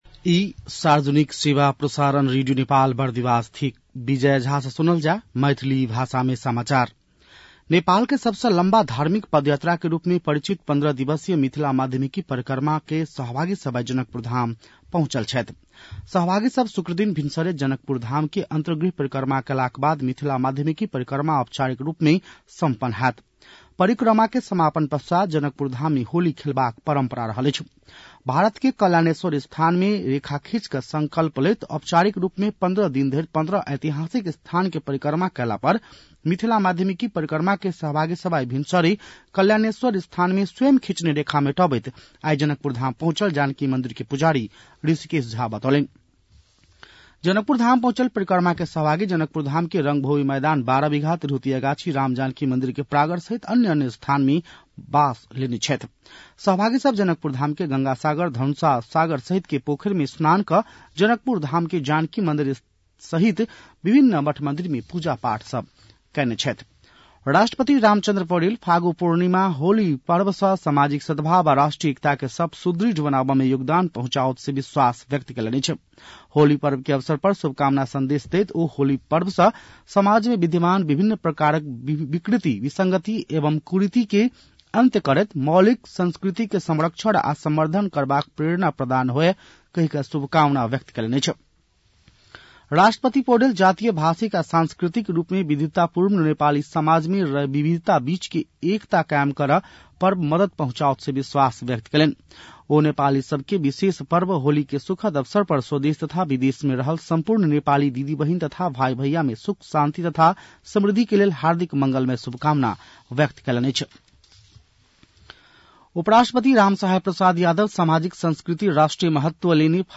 मैथिली भाषामा समाचार : ३० फागुन , २०८१